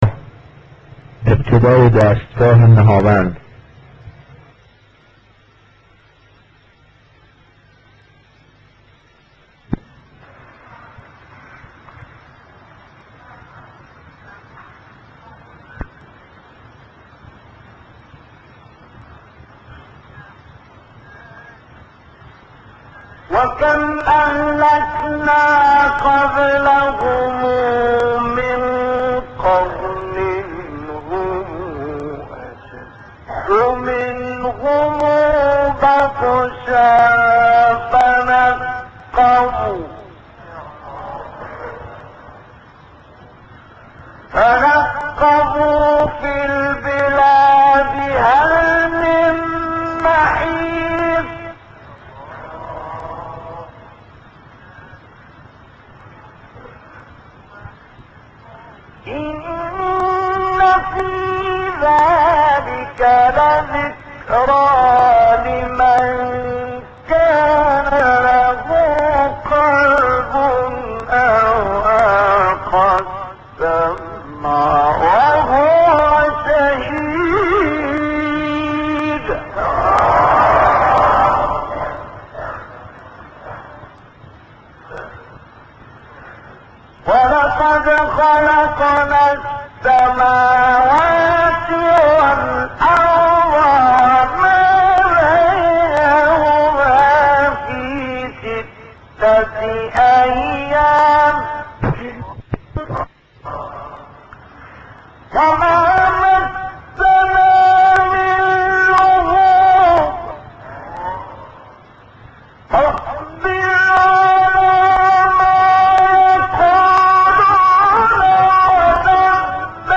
نهاوند مصطفی اسماعیل آیه 36 سوره ق.mp3
نهاوند-مصطفی-اسماعیل-آیه-36-سوره-ق.mp3